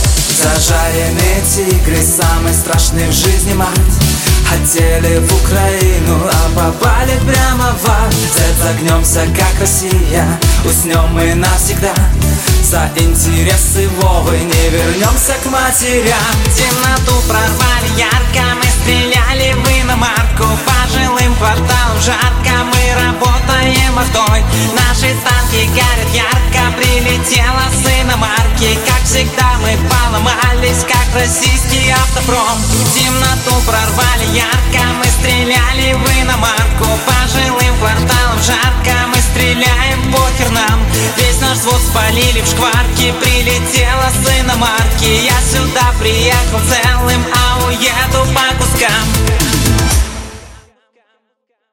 громкие
пародия